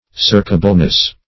searchableness - definition of searchableness - synonyms, pronunciation, spelling from Free Dictionary
Searchableness \Search"a*ble*ness\, n.